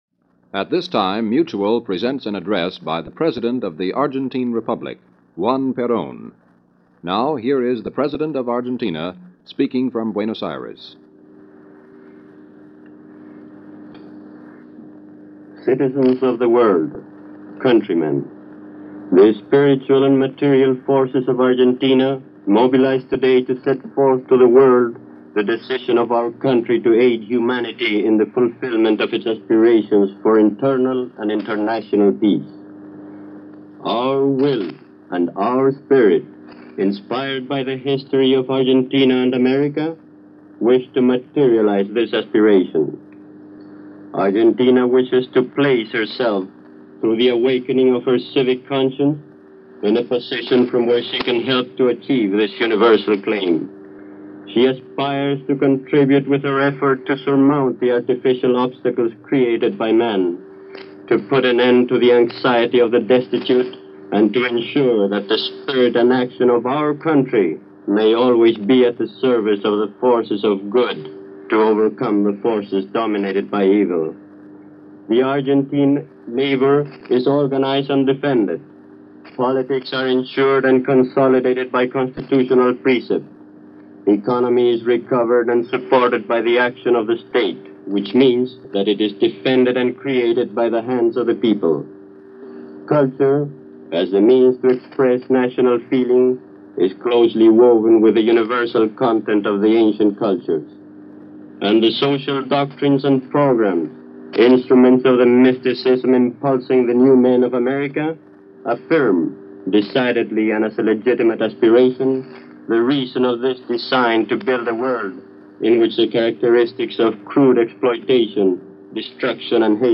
Billed as “An Address to the World”, Argentine President Juan Perón went before microphones from the Presidential Palace in Buenos Aires to deliver a speech of solidarity and support to the efforts of achieving peace throughout the world on July 6, 1947.
juan-peron-address-july-6-1947.mp3